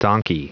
Prononciation du mot donkey en anglais (fichier audio)
Prononciation du mot : donkey